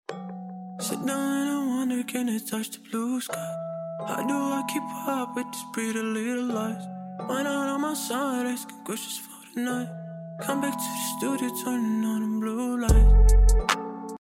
Late night feels slowed down sound effects free download